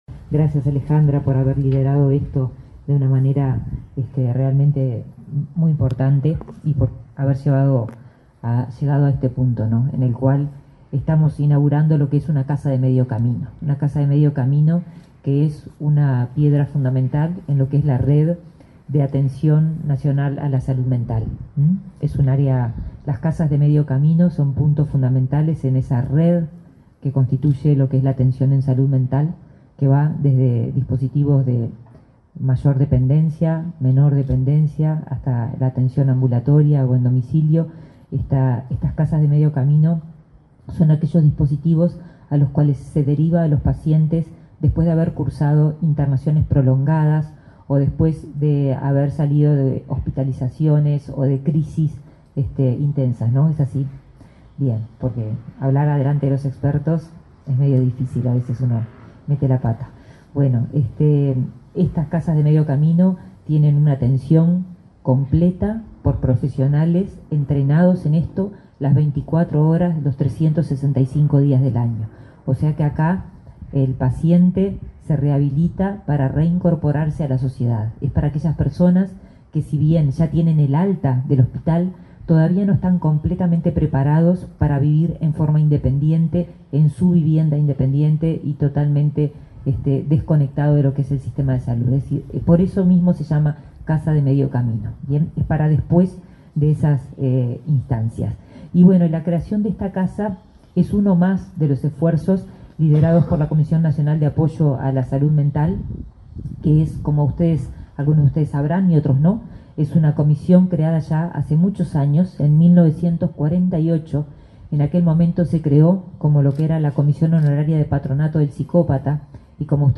Palabras de autoridades en inauguración de casa de medio camino
Palabras de autoridades en inauguración de casa de medio camino 09/10/2024 Compartir Facebook X Copiar enlace WhatsApp LinkedIn La ministra de Salud Pública, Karina Rando; su par de Desarrollo Social, Alejandro Sciarra, y el subsecretario de Salud Pública, José Luis Satdjian, participaron, este miércoles 9 en Montevideo, de la inauguración de la casa de medio camino "Jacarandá”.